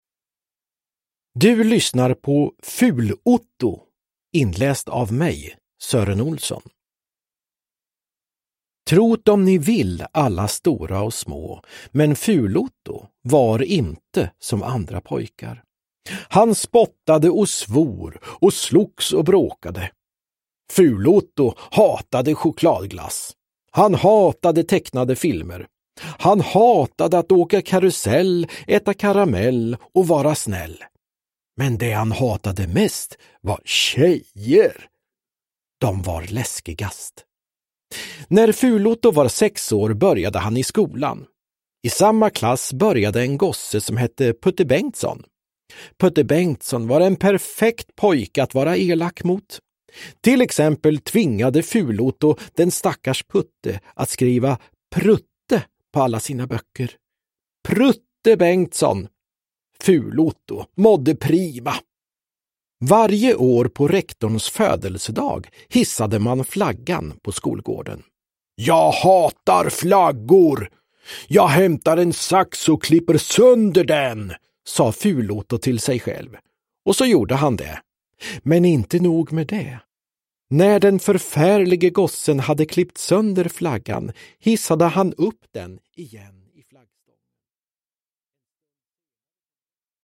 Ful-Otto – Ljudbok – Laddas ner
Uppläsare: Sören Olsson, Anders Jacobsson